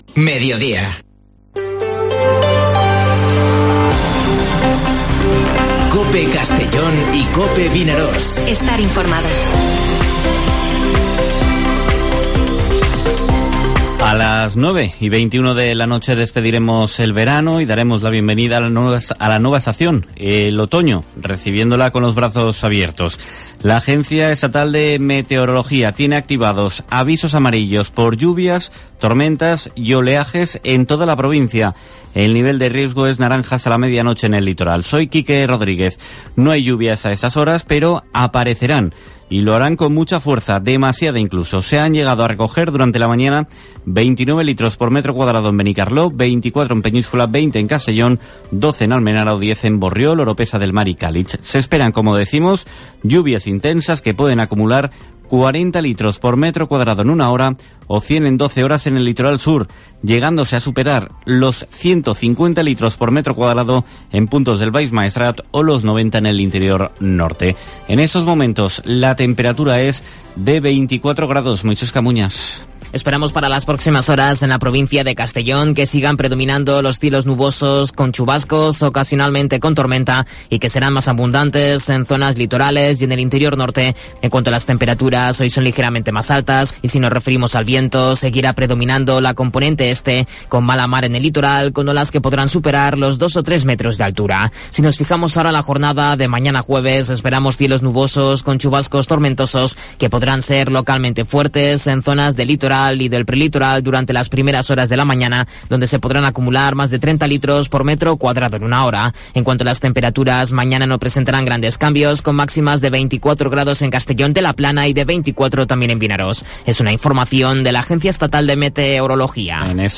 Informativo Mediodía COPE en la provincia de Castellón (22/09/2021)